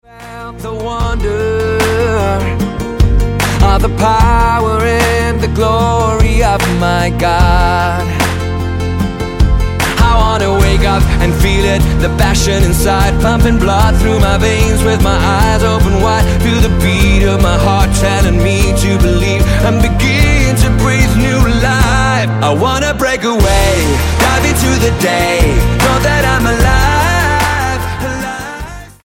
STYLE: Latin